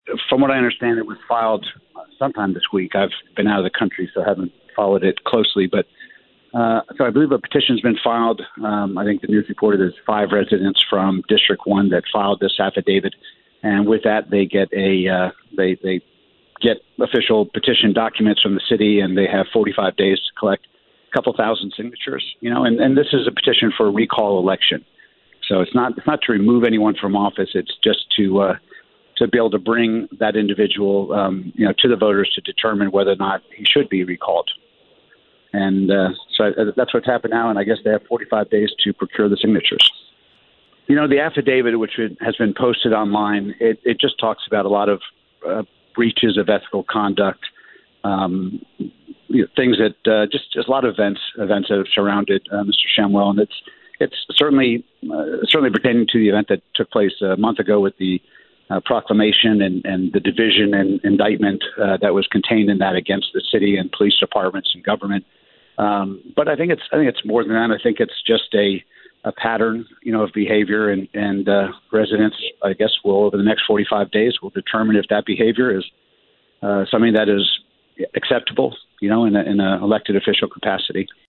McKinney Mayor George Fuller says that about a half dozens residents launched the petition drive: